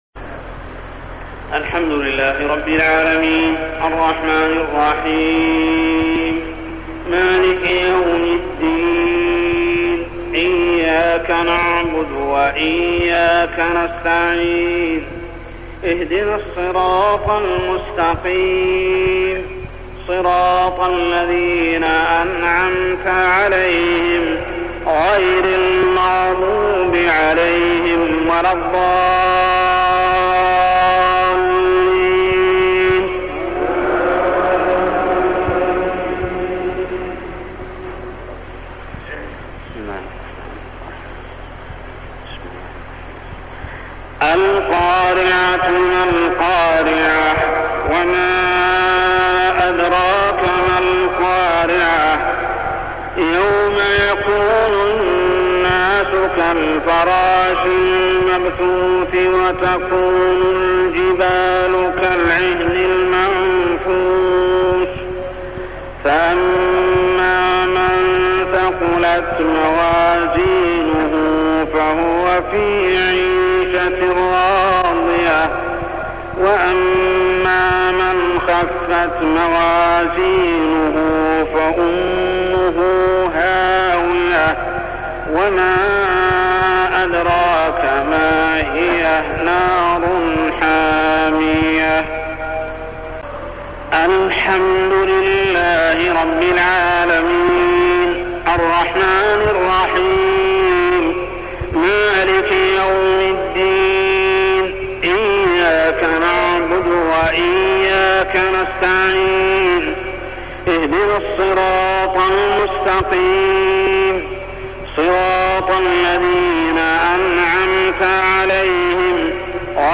تلاوة من صلاة المغرب لسورتي القارعة و الماعون كاملة حدود عاميّ 1398هـ - 1399هـ | Maghrib prayer Surah Al-Qariah and Al-Ma'un > 1399 🕋 > الفروض - تلاوات الحرمين